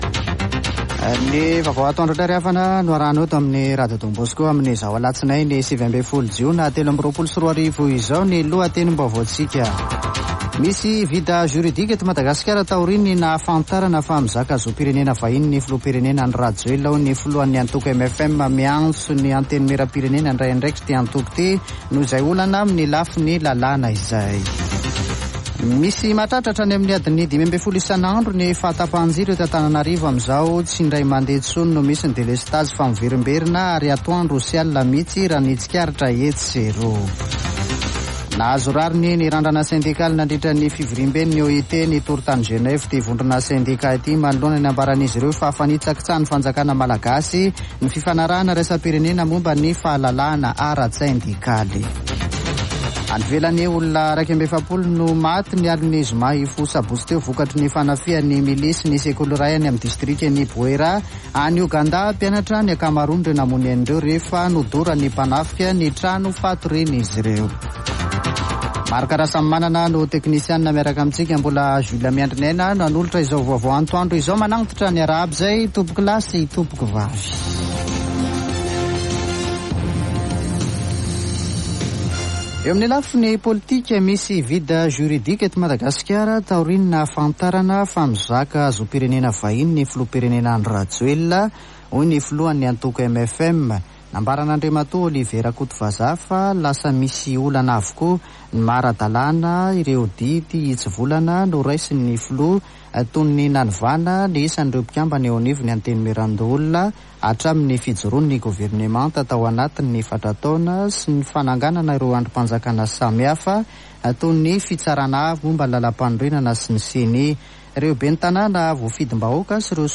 [Vaovao antoandro] Alatsinainy 19 jona 2023